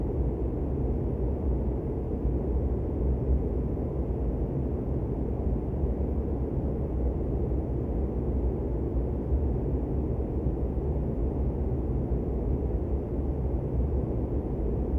H145_Wind_In-right.wav